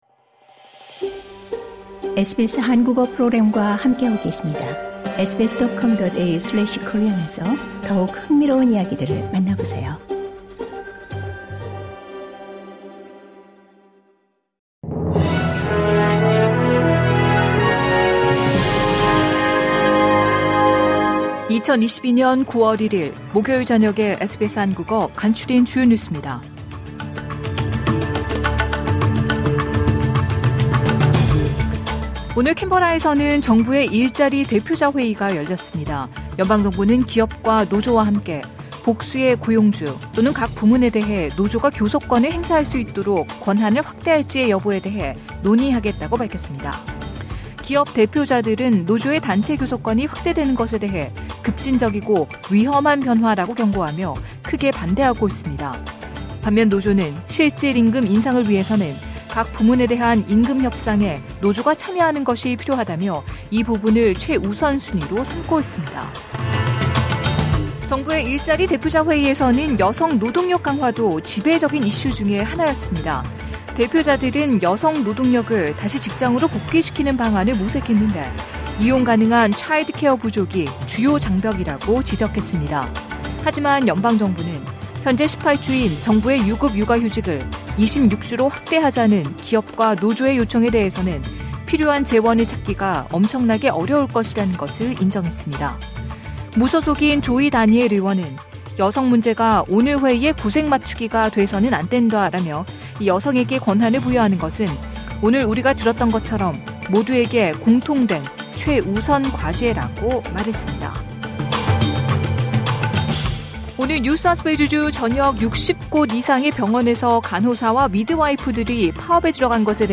2022년 9월 1일 목요일 저녁 SBS 한국어 간추린 주요 뉴스입니다.